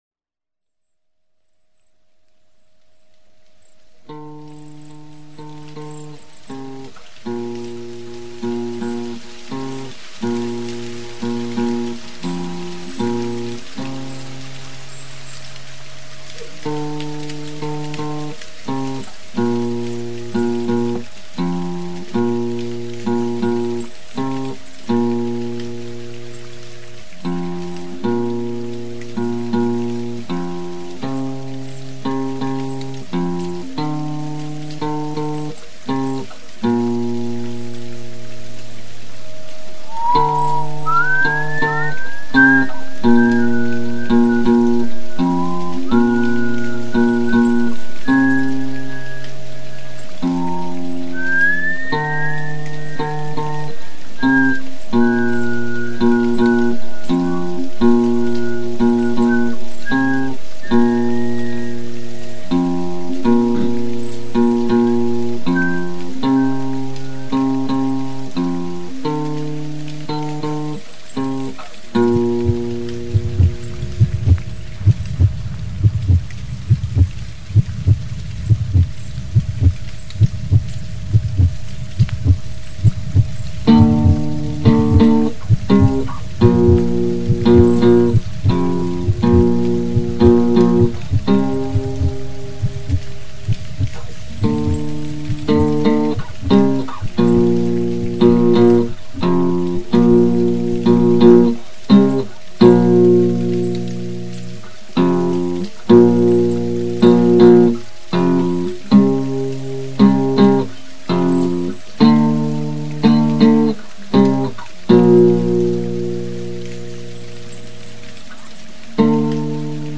These Are The Purest Forms Of These Songs!